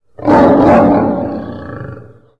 lion3.mp3